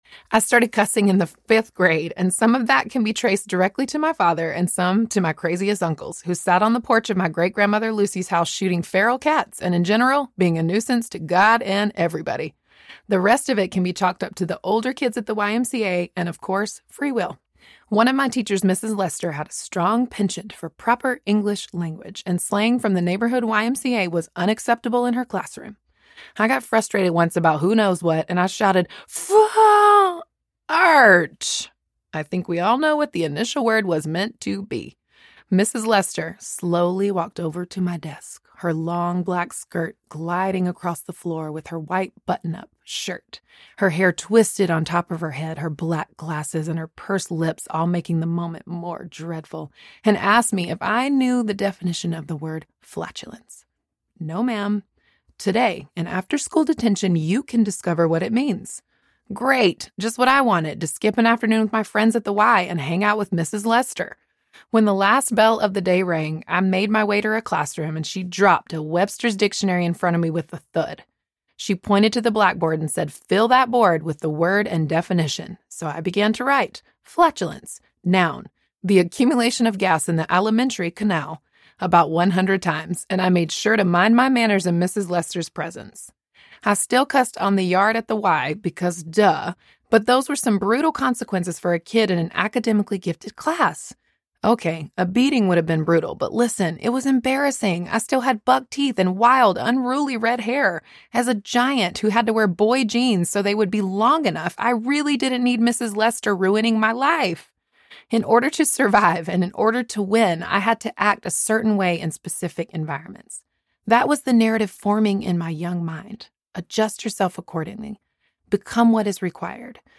Rise of the Truth Teller Audiobook